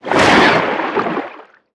Sfx_creature_snowstalkerbaby_flinch_swim_03.ogg